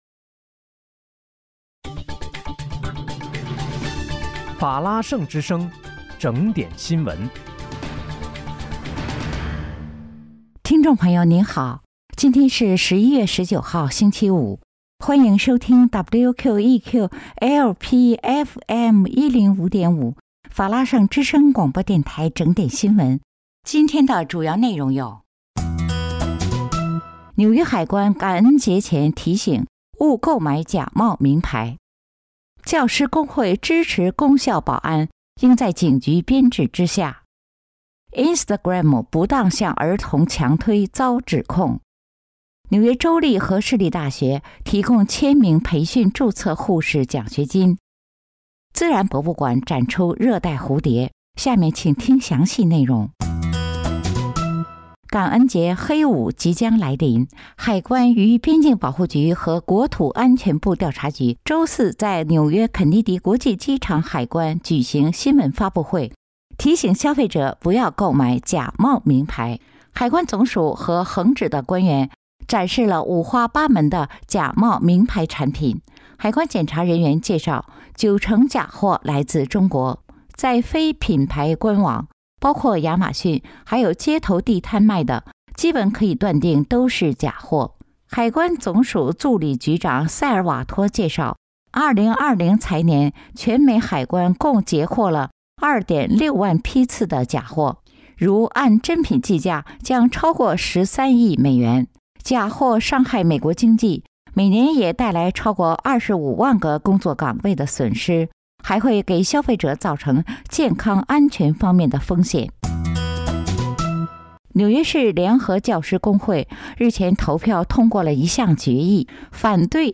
11月19日（星期五）纽约整点新闻